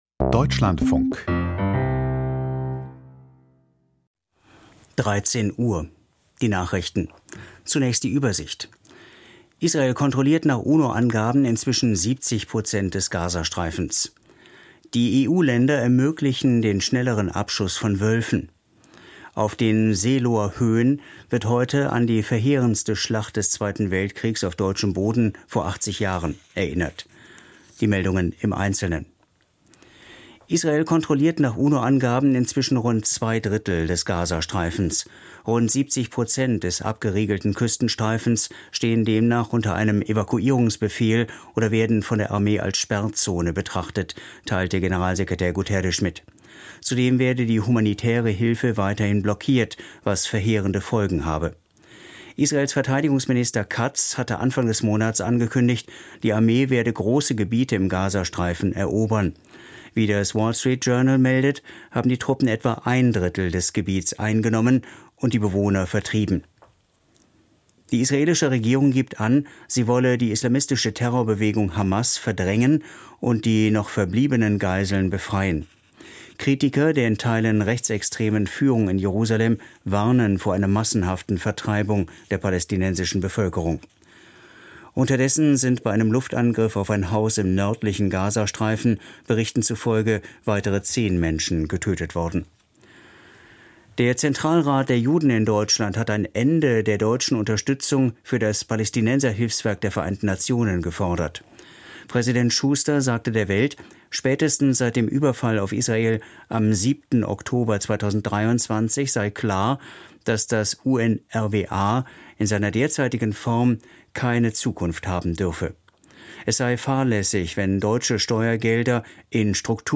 Die Deutschlandfunk-Nachrichten vom 16.04.2025, 13:00 Uhr